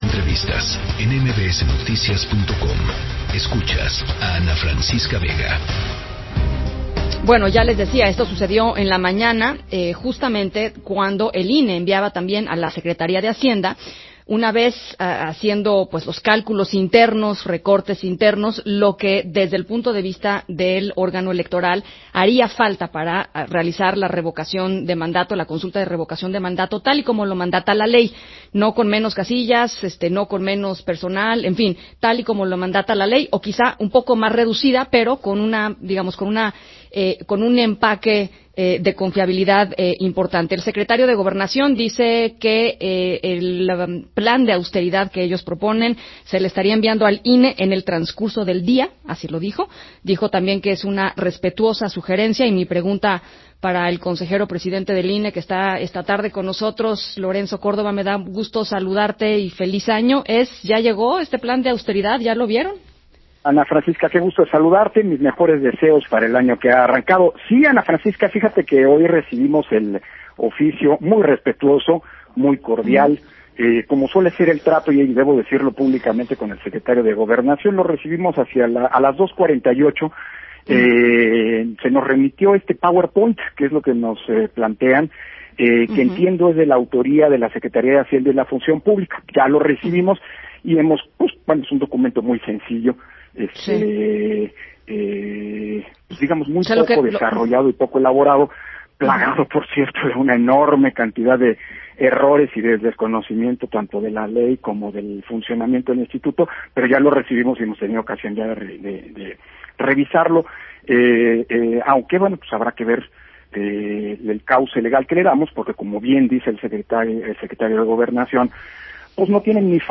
INE quiere hacer Revocación de Mandato conforme a la ley, aunque la situación no es la ideal; Lorenzo Córdova en entrevista con Ana Francisca Vega